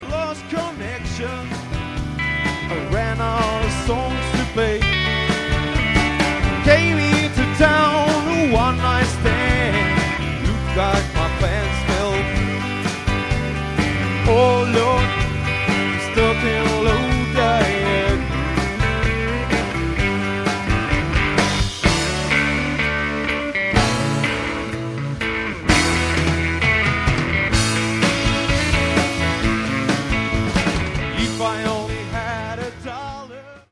Pro höör på MP3 betona från live konsertta 1999: